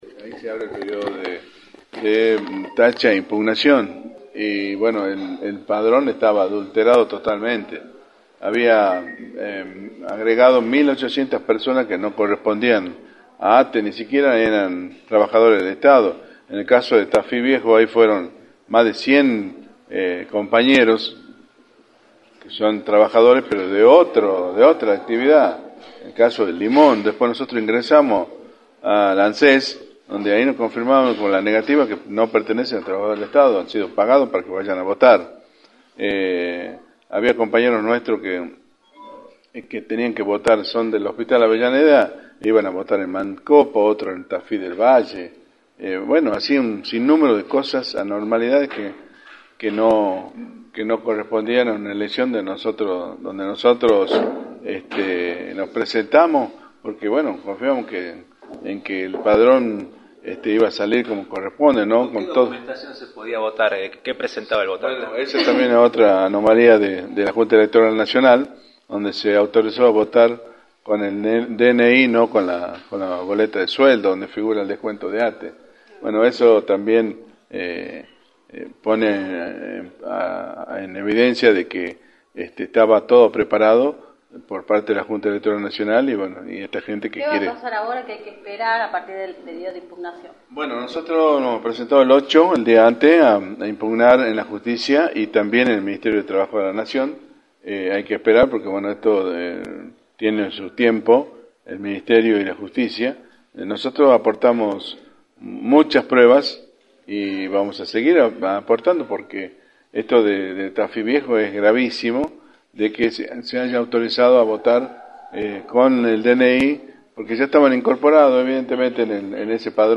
entrevista para «La Mañana del Plata» por la 93.9.